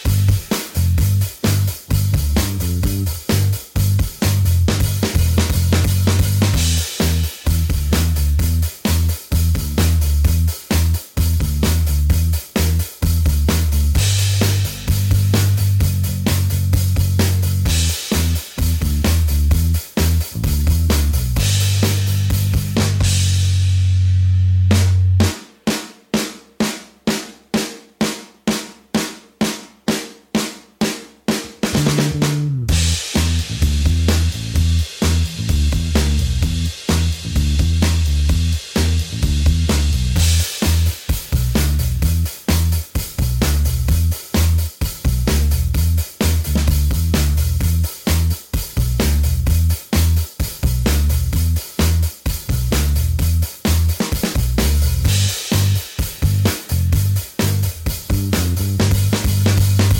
Minus Main Guitar For Guitarists 3:33 Buy £1.50